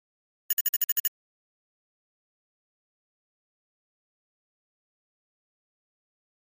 Flash Readout High Frequency Electronic Clicks